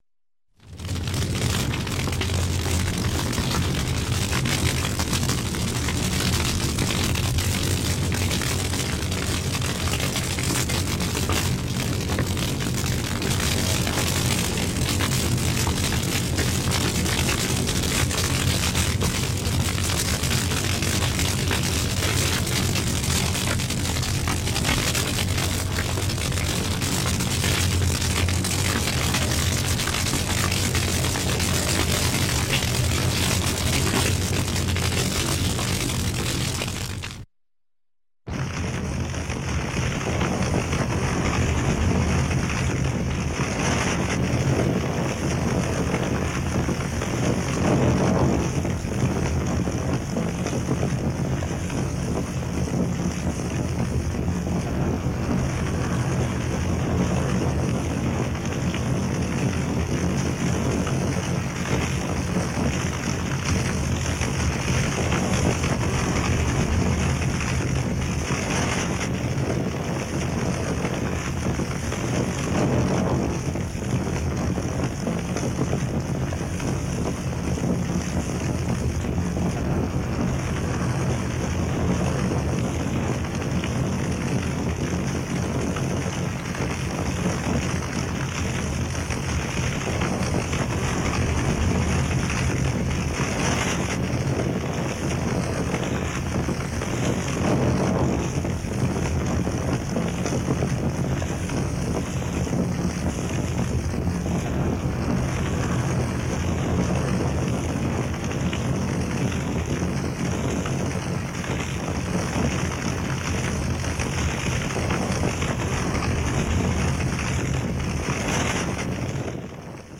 Огонь: сильное горение, рев пламени, пожар
Огонь, пламя